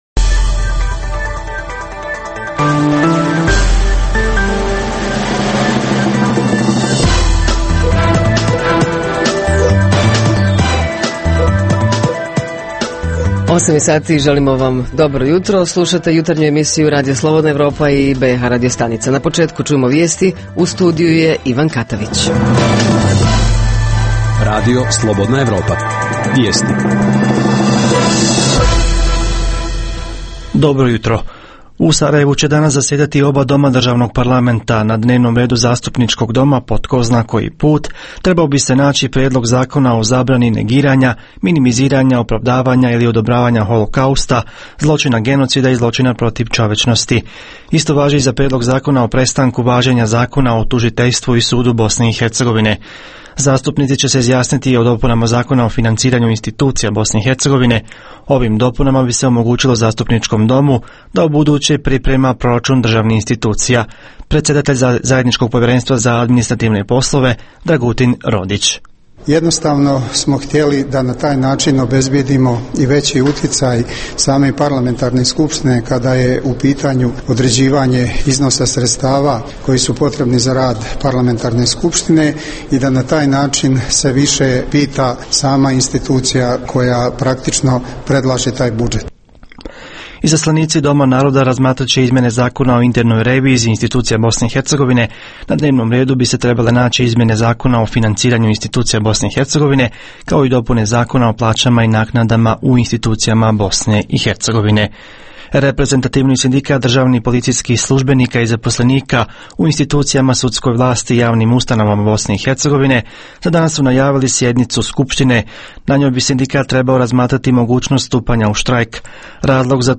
Jutarnji program za BiH koji se emituje uživo. Tema jutra: Da li smo spremni odseliti iz svog grada u neki drugi, unutar svoje države, radi posla? Reporteri iz cijele BiH javljaju o najaktuelnijim događajima u njihovim sredinama.
Redovni sadržaji jutarnjeg programa za BiH su i vijesti i muzika.